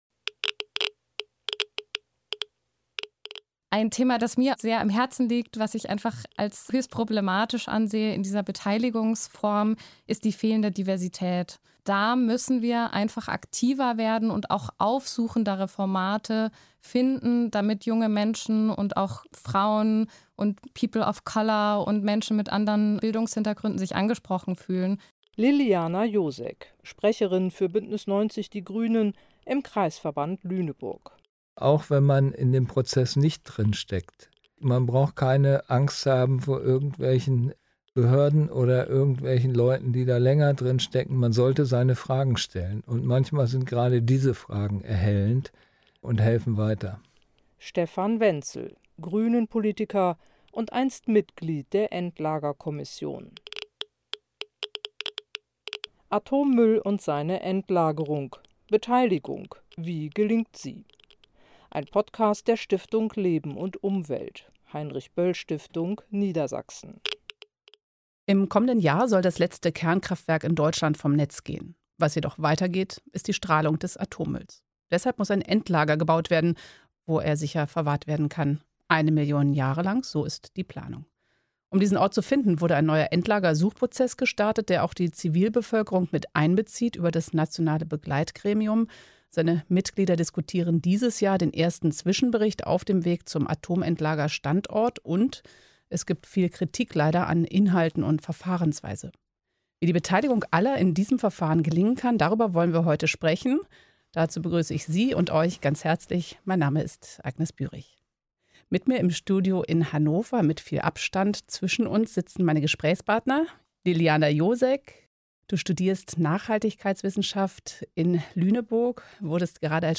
Interview und Produktion